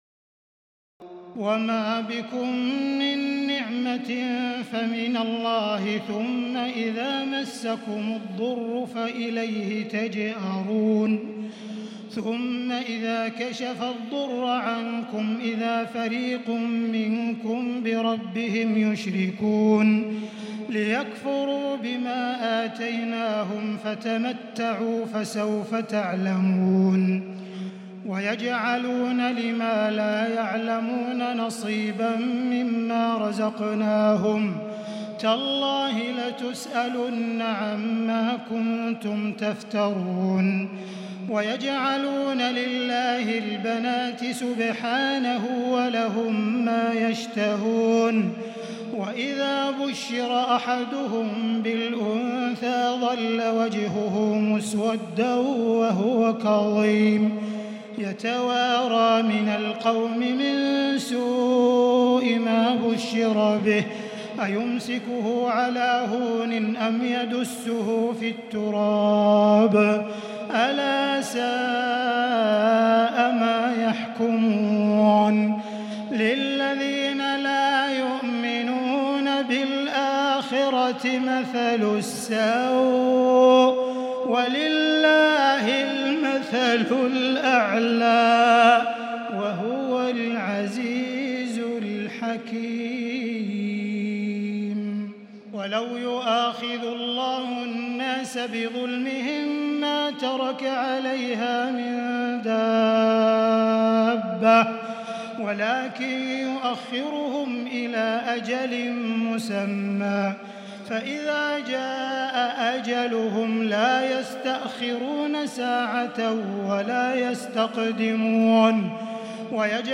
تراويح الليلة الثالثة عشر رمضان 1438هـ من سورة النحل (53-128) Taraweeh 13 st night Ramadan 1438H from Surah An-Nahl > تراويح الحرم المكي عام 1438 🕋 > التراويح - تلاوات الحرمين